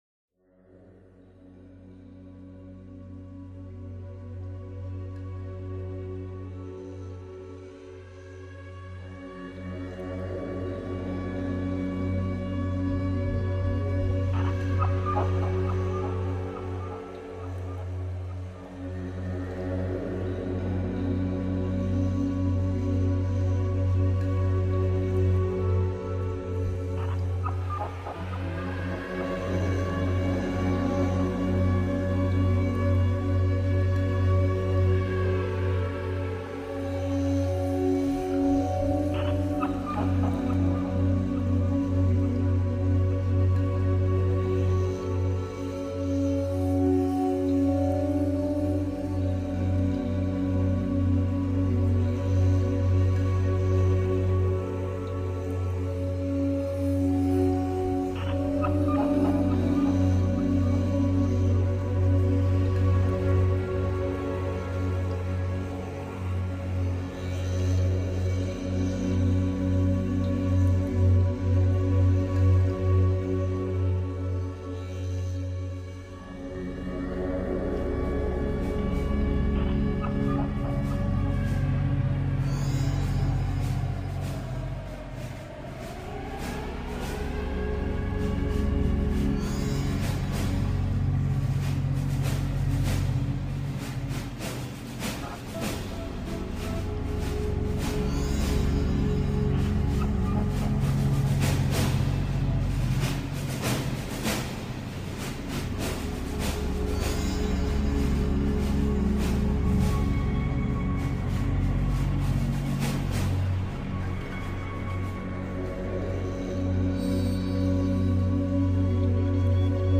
synkkämielinen ja ruosteenmakuinen musiikki on ajatonta